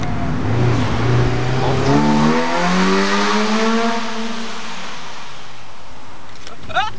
Car le bruit rauque et fort du filtre JR laisse place à un bruit moins fort mais plus pointu à partir, toujours, de 4000 tr/min..